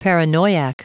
Prononciation du mot paranoiac en anglais (fichier audio)